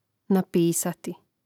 napísati napisati